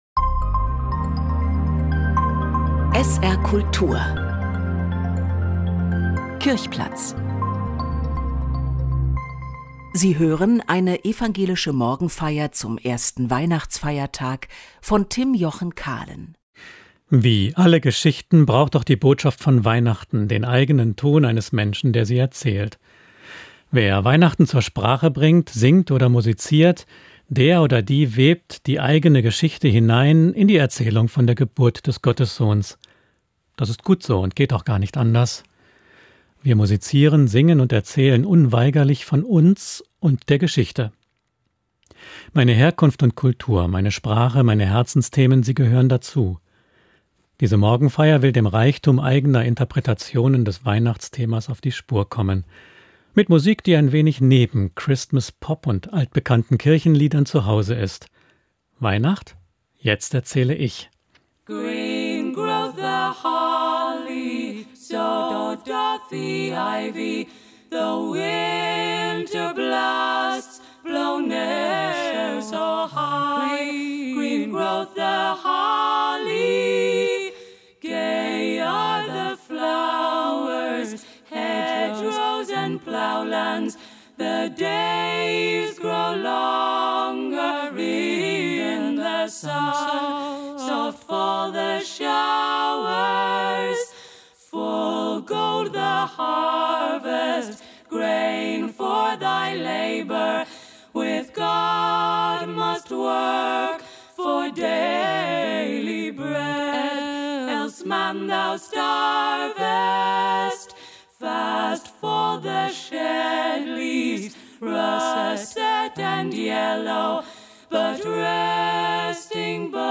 Diese Morgenfeier will dem Reichtum eigener Interpretationen des Weihnachtsthemas auf die Spur kommen. Mit Musik, die ein wenig neben Christmas-Pop und altbekannten Kirchenliedern zuhause ist.